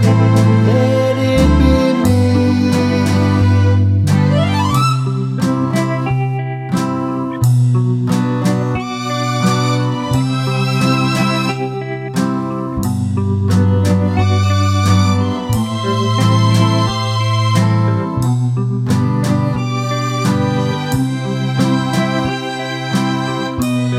No Harmony Pop (1950s) 2:37 Buy £1.50